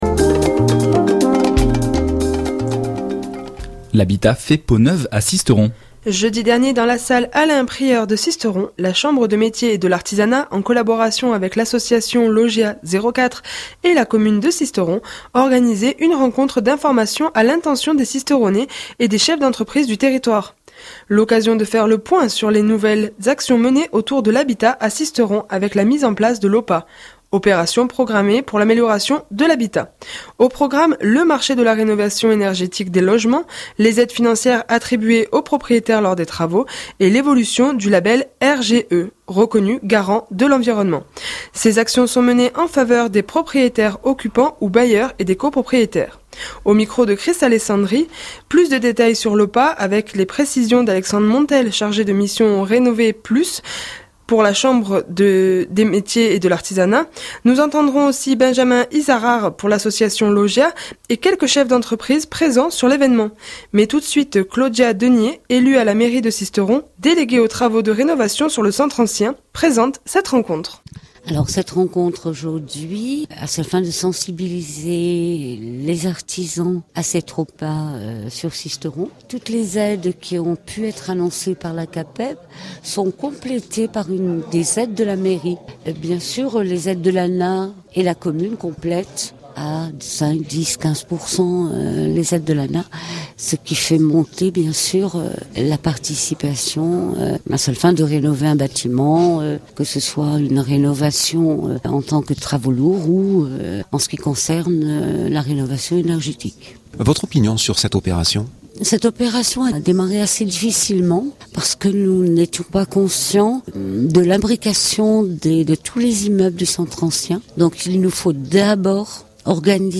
Mais tout de suite, Colette Denié, élue à la mairie de Sisteron, déléguée aux travaux de rénovation sur le centre ancien, présente cette rencontre. écouter durée:8'48'' JR-2018-03-29 - Sisteron-OPAH.mp3 (7.98 Mo)